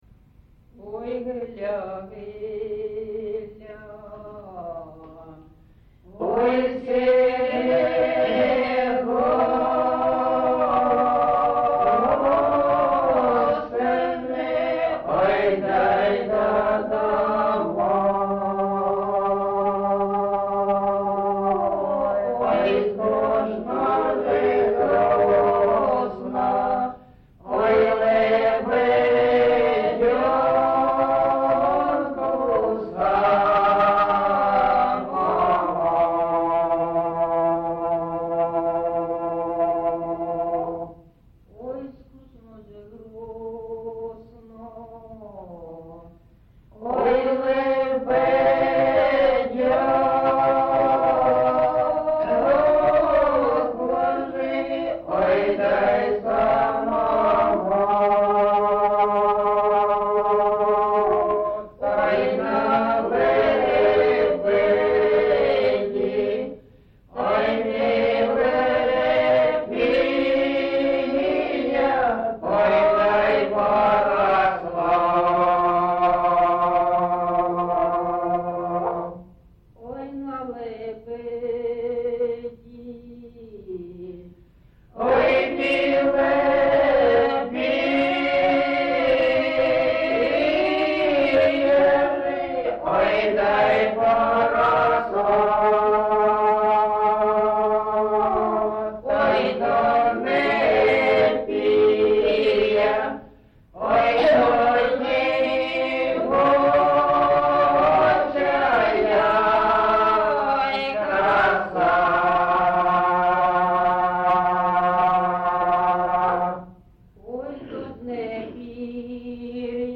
GenrePersonal and Family Life, Ballad
Recording locationMarynivka, Shakhtarskyi (Horlivskyi) district, Donetsk obl., Ukraine, Sloboda Ukraine